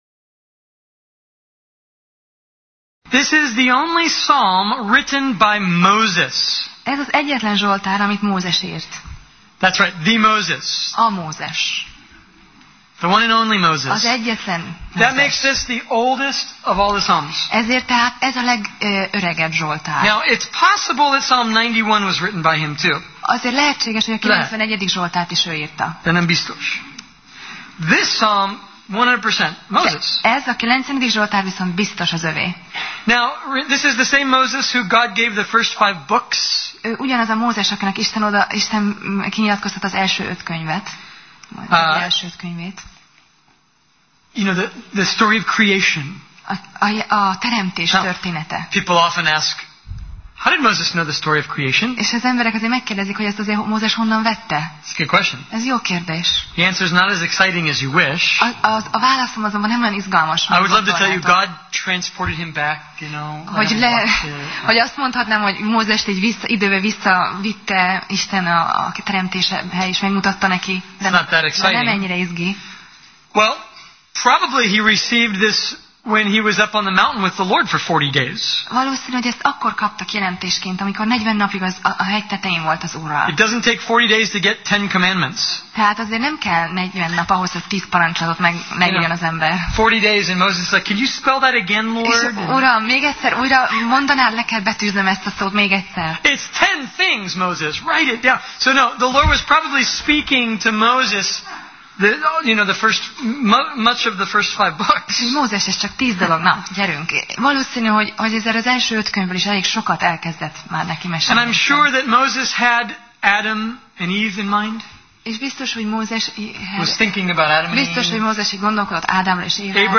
Alkalom: Szerda Este